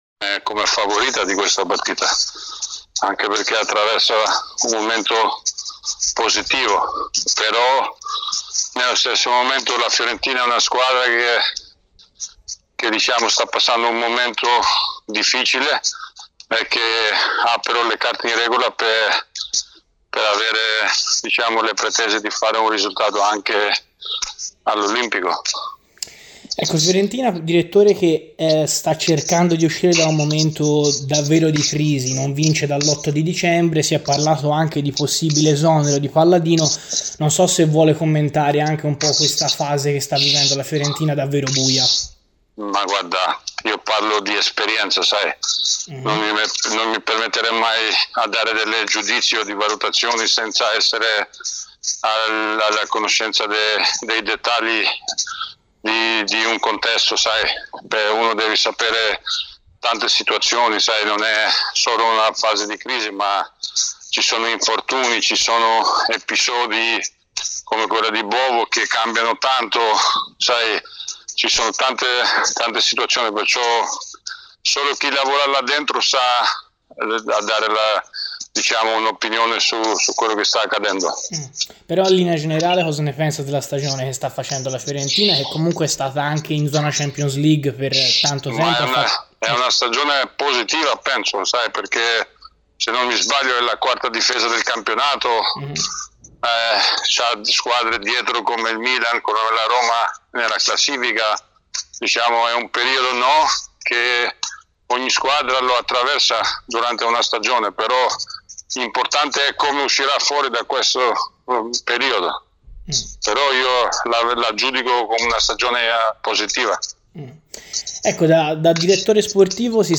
In avvicinamento a Lazio-Fiorentina, ai microfoni di Radio FirenzeViola ha preso la parola Igli Tare, storica figura legata ai biancocelesti - prima da giocatore e poi per tanti anni da direttore sportivo -.